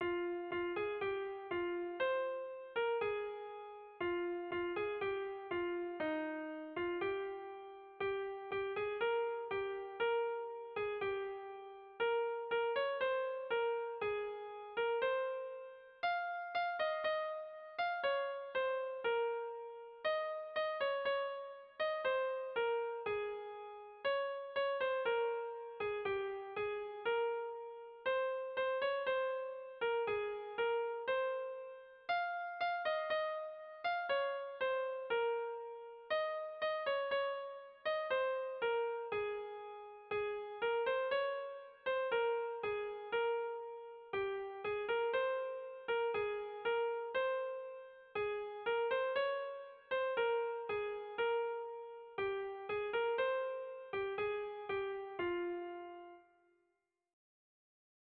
Kontakizunezkoa
Hamabiko berdina, 6 puntuz eta 8 silabaz (hg) / Sei puntuko berdina, 16 silabaz (ip)
A-B-C-D